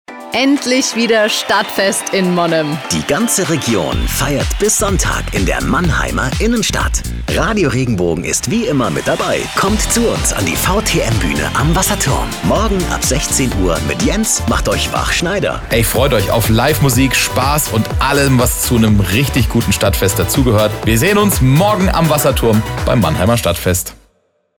Spotbeispiele für die Vorbewerbung